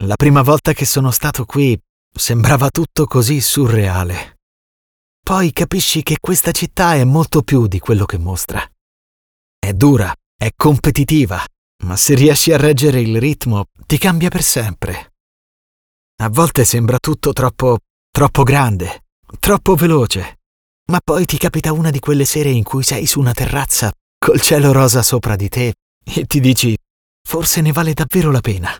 Male
Bright, Engaging, Friendly, Versatile, Authoritative, Character
Videogame Dubbing.mp3
Microphone: Neumann TLM 103, Universal Audio Sphere Dlx